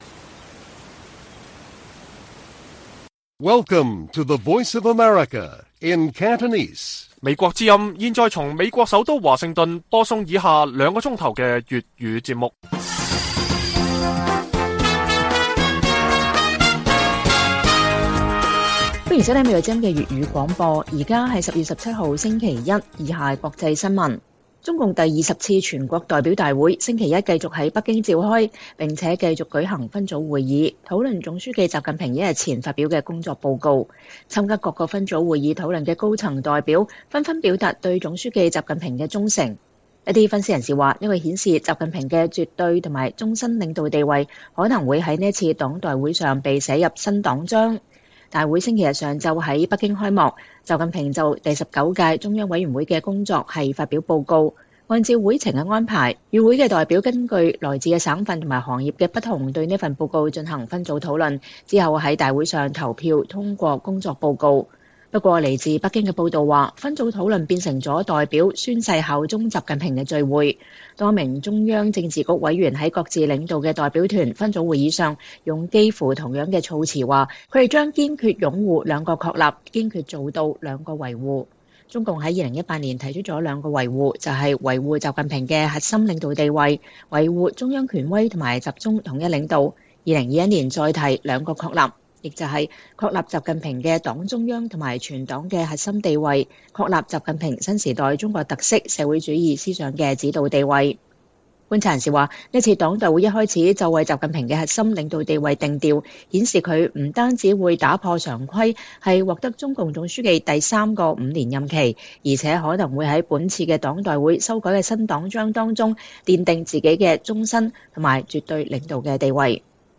粵語新聞 晚上9-10點: 中共黨代會猶如效忠習近平宣誓大會